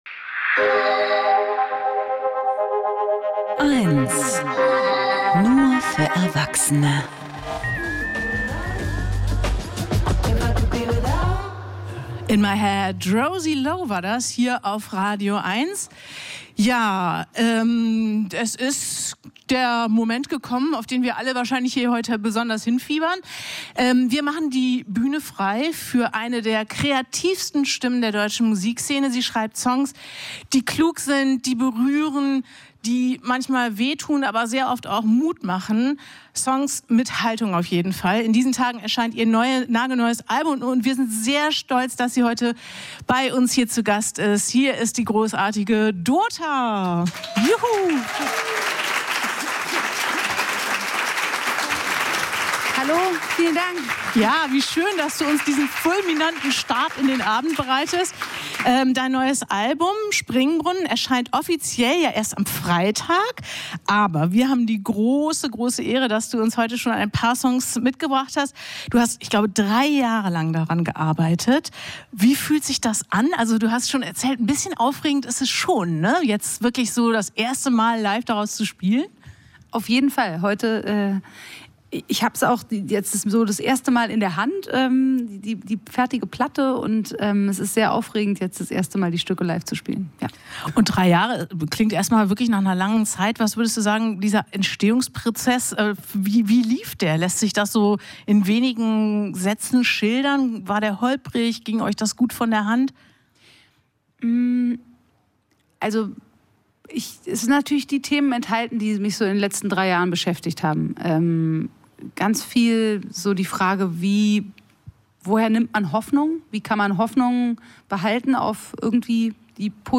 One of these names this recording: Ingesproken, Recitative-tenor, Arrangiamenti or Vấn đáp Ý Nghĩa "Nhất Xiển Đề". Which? Ingesproken